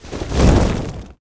ignite.ogg